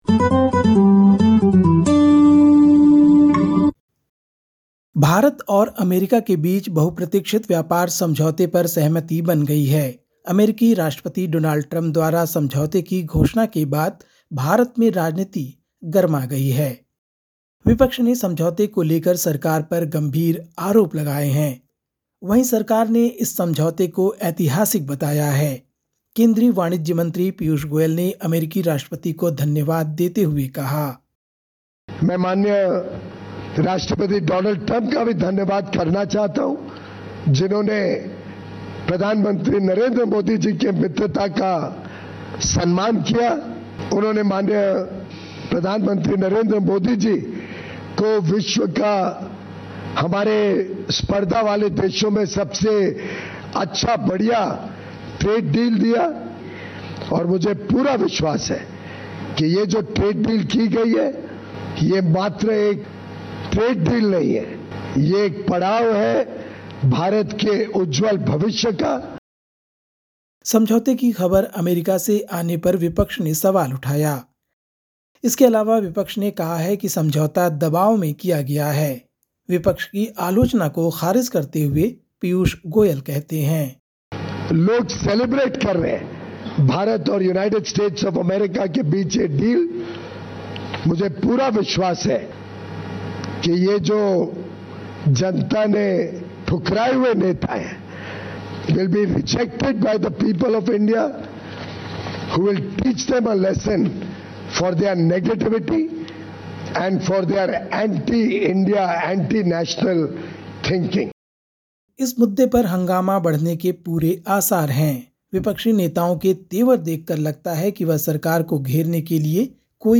India report: Government hails India-US trade deal as ‘historic and forward-looking'
Listen to the latest SBS Hindi news from India. 04/02/2026